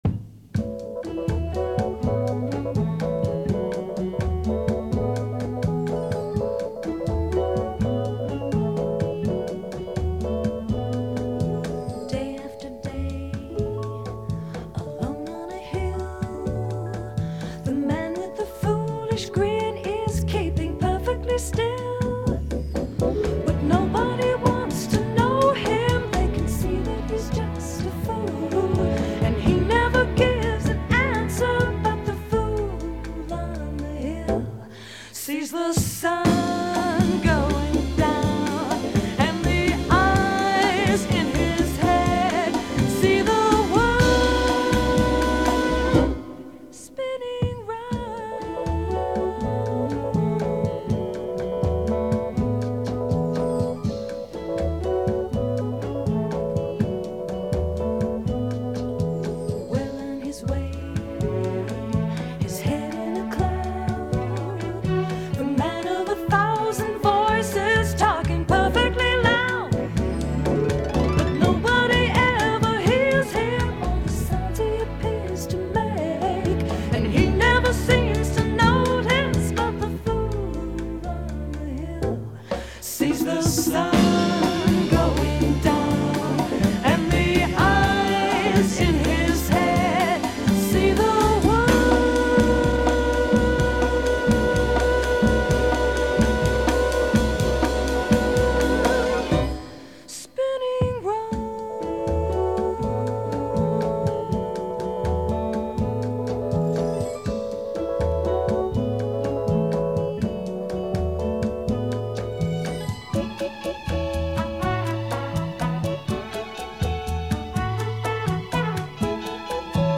ritmos brasileños